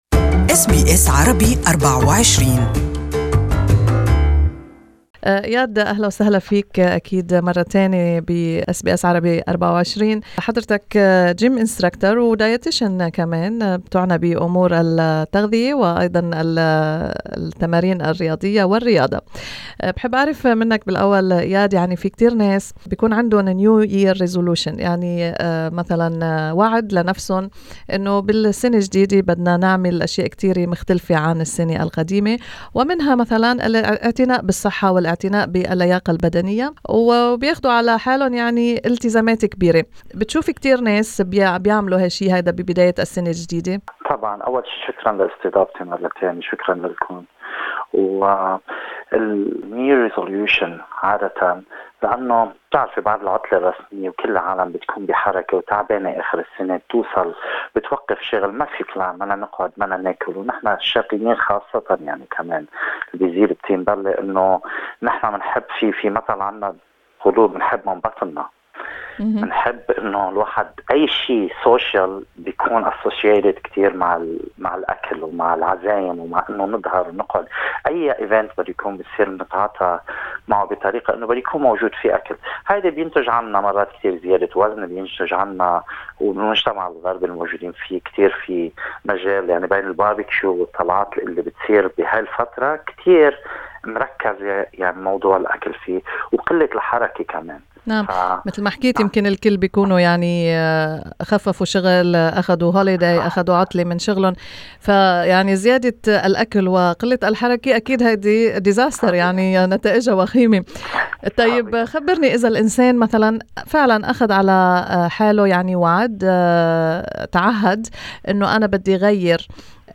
استمعوا إلى اللقاء كاملا تحت الشريط الصوتي.